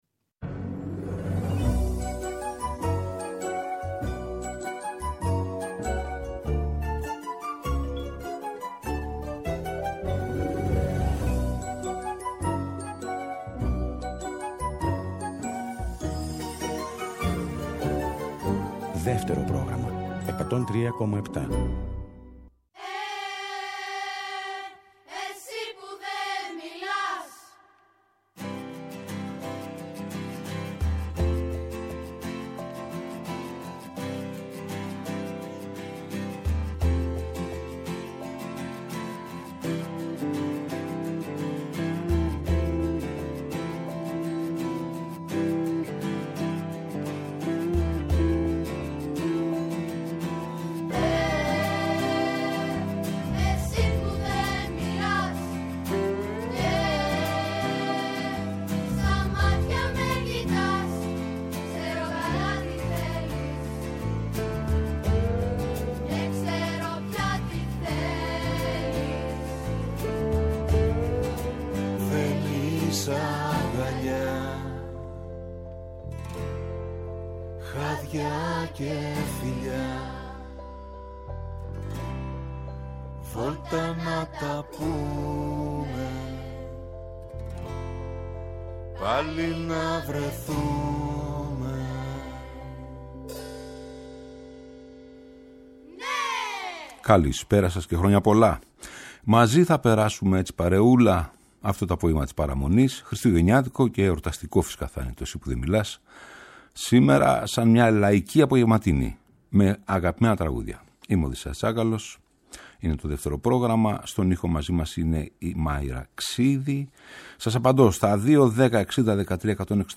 Κάτι σαν μία Λαϊκή απογευματινή με αγαπημένα στον χρόνο τραγούδια.